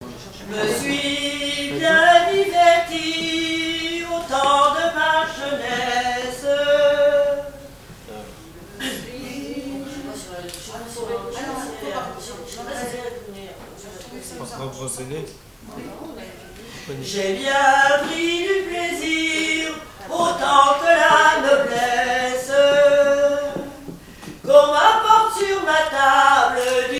Genre strophique
7e festival du chant traditionnel : Collectif-veillée
Pièce musicale inédite